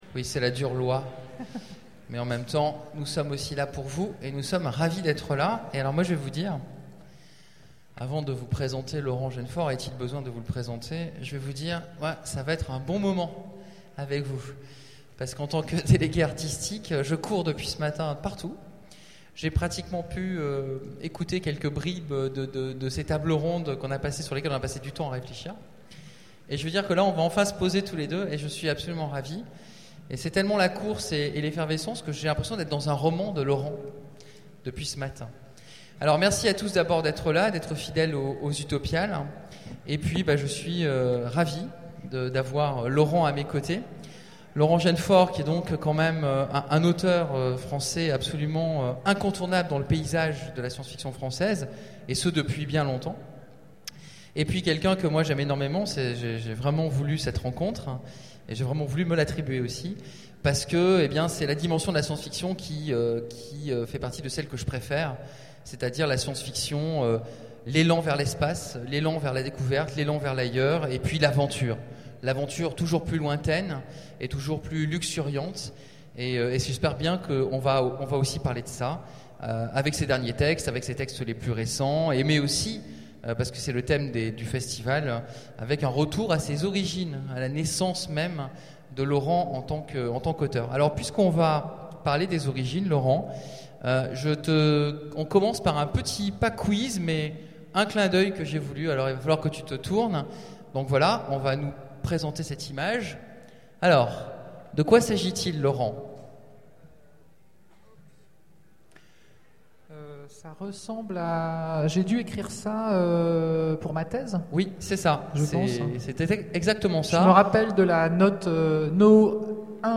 Conférence
Mots-clés Rencontre avec un auteur Conférence Partager cet article